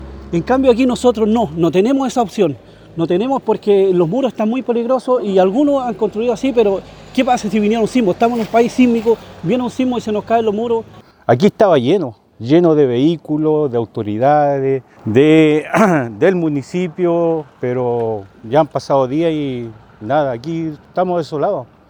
cuna-geo-chile-mix-vecinos.mp3